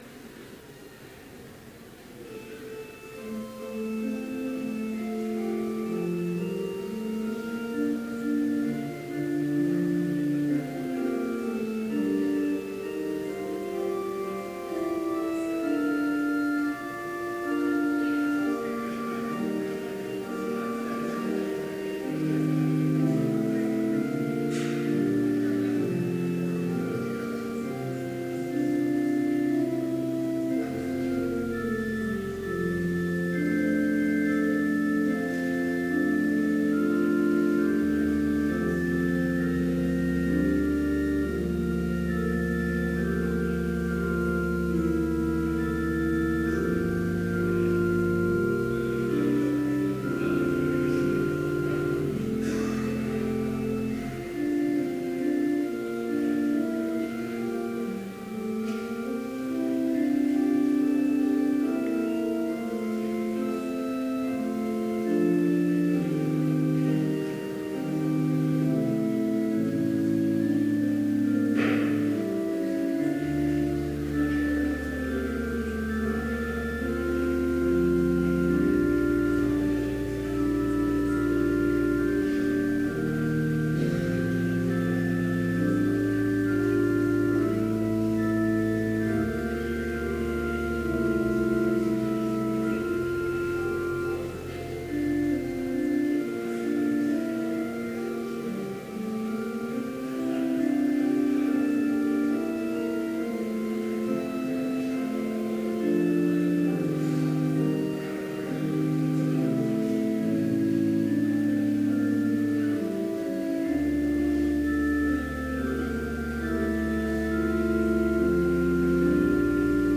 Complete service audio for Chapel - March 15, 2017